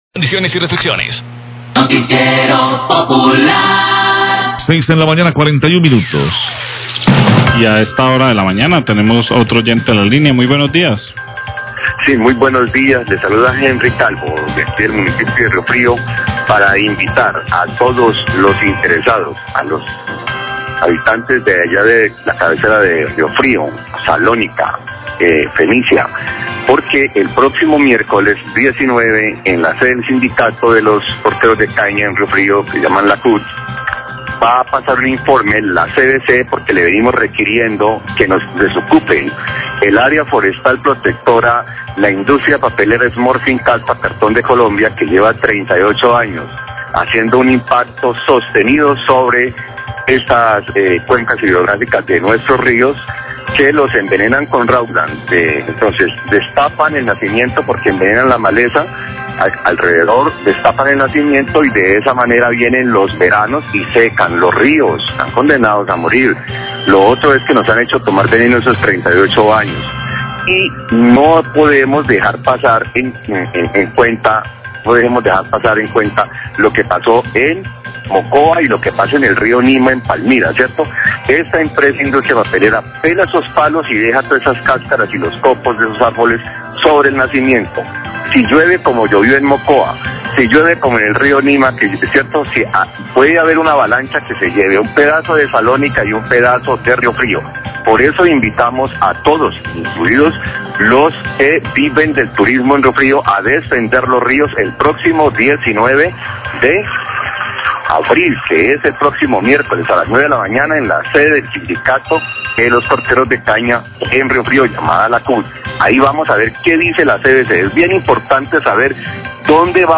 Radio
Oyente